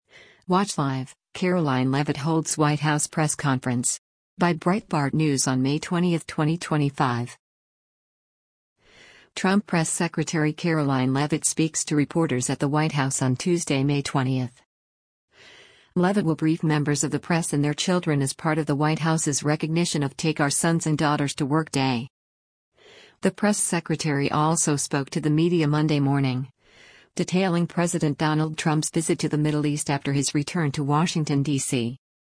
Trump Press Secretary Karoline Leavitt speaks to reporters at the White House on Tuesday, May 20.